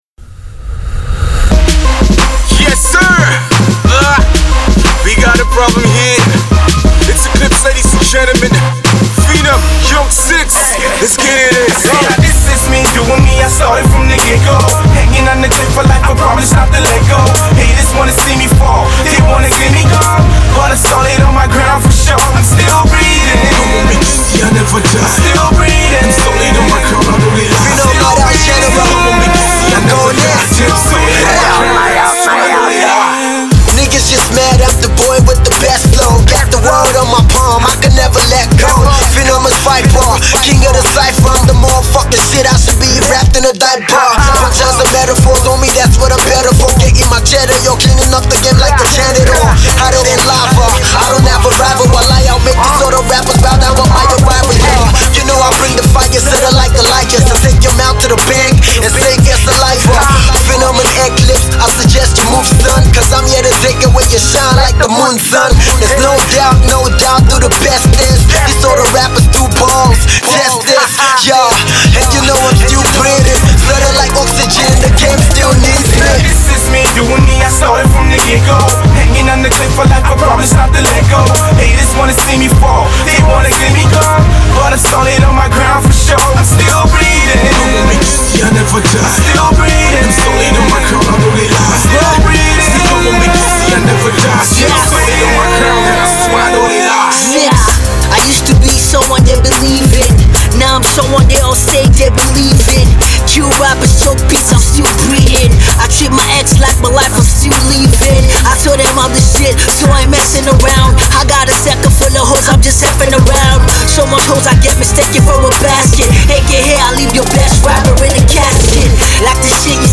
embodies the new school of Hip-Hop in Nigeria